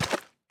1.21.5 / assets / minecraft / sounds / mob / strider / step4.ogg